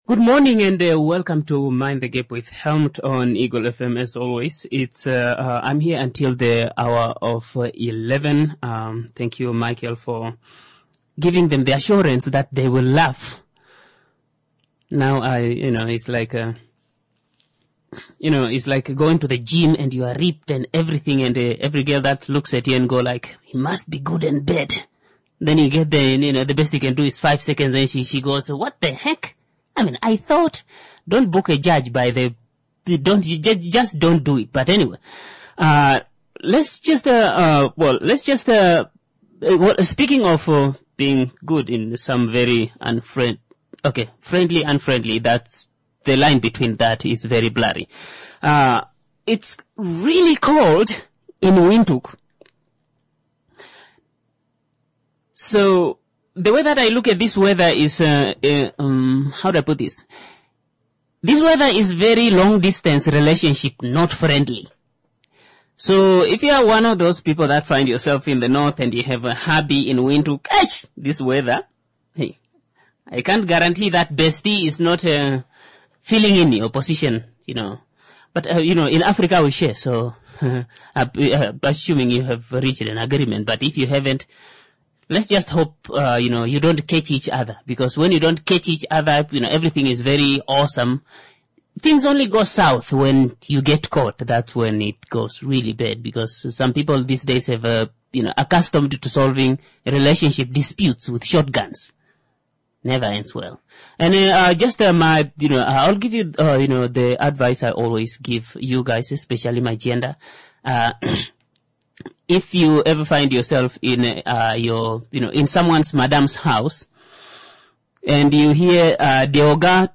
⚠ This is a COMEDY show.